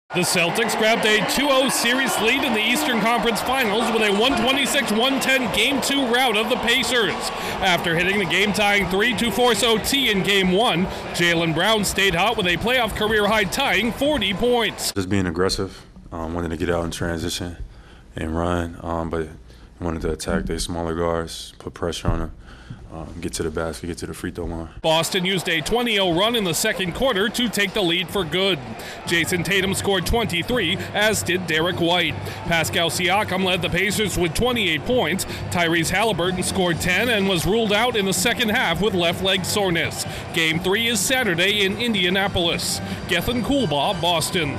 A career scoring night for a Celtics star powers his team past the Pacers. Correspondent